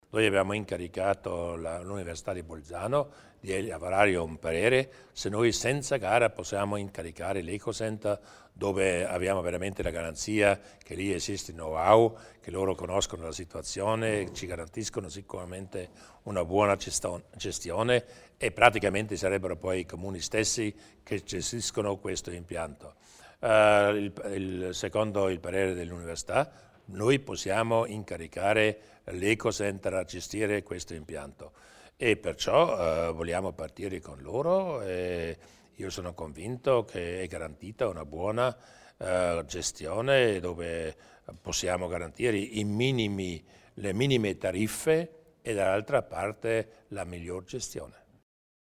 Il Presidente Durnwalder spiega la futura gestione del termovalorizzatore